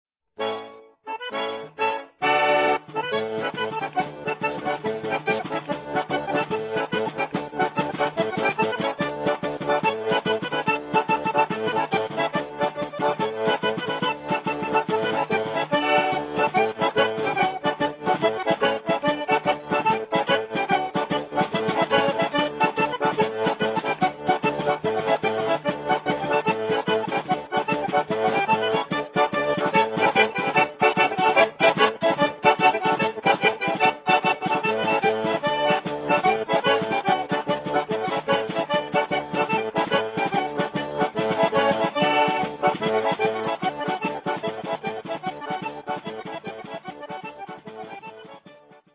Musica tradizionale dalla Majella e dalla Val Pescara
This impression is magnified by the nature of the ddu' bbottë itself which, because of its limitations, produces music which is far more diatonic in style than is a lot of Italian organetto (melodeon) playing - particularly the more modern stuff.
play Sound ClipClearly, with a 36 track CD, it's not possible to comment on all of them, particularly when the great majority are rather similar; here's a bit of track 1 (sound clip right - Saltarella con tamburo).